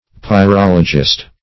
Search Result for " pyrologist" : The Collaborative International Dictionary of English v.0.48: Pyrologist \Py*rol"o*gist\, n. One who is versed in, or makes a study of, pyrology.
pyrologist.mp3